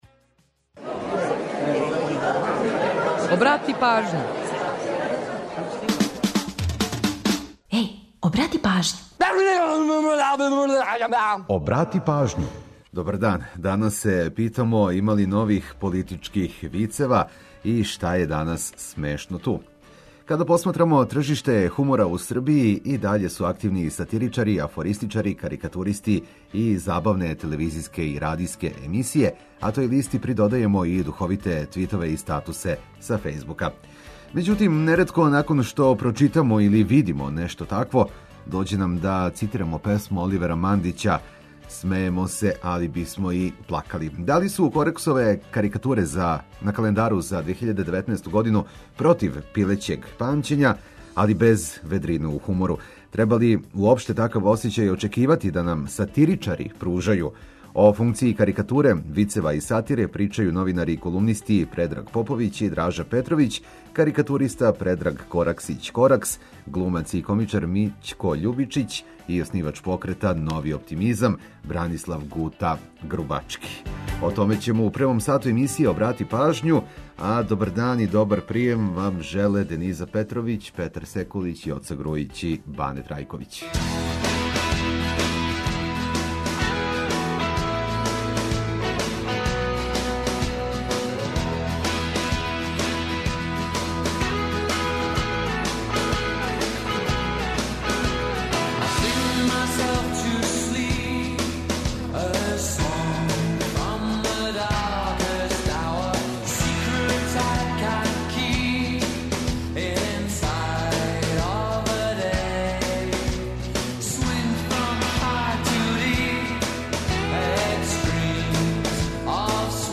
Водимо вас и на концерт групе „Џинкс” у Дому омладине Београда, пажљиво ослушкујте!